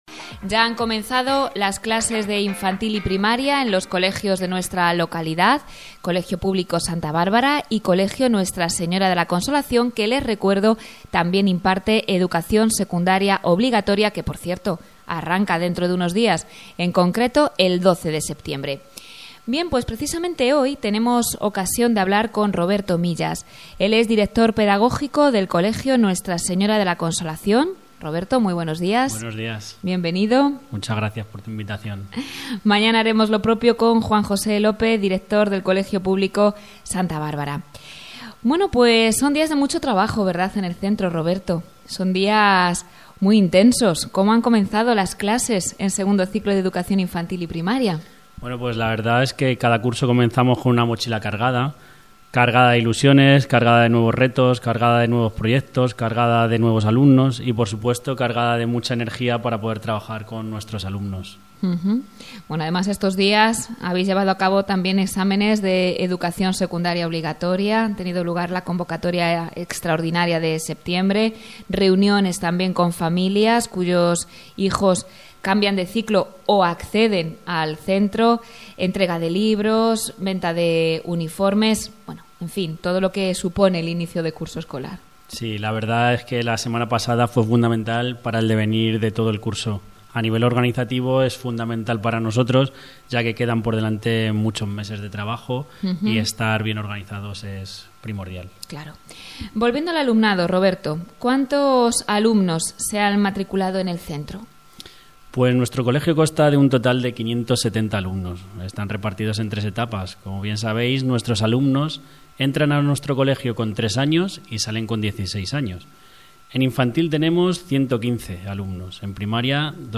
PROGRAMA DE RADIO SOBRE EL INICIO DE CURSO